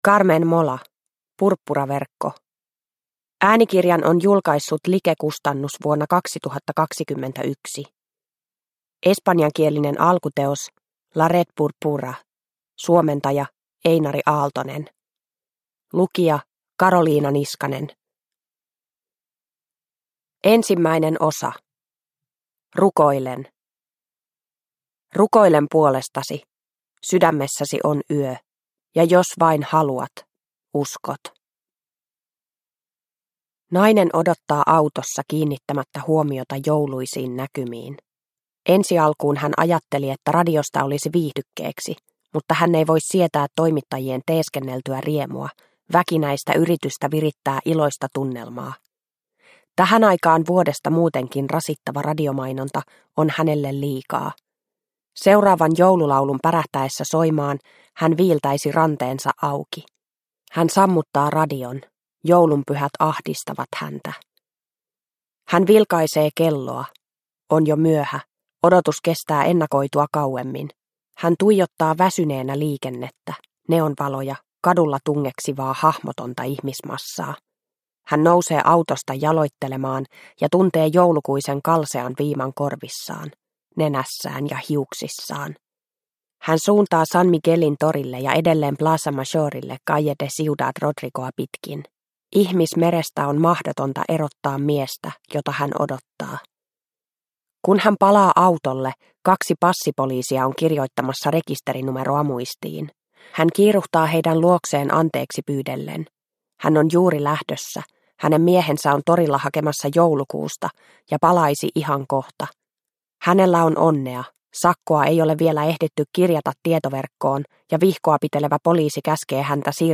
Purppuraverkko – Ljudbok – Laddas ner